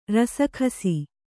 ♪ rasa khasi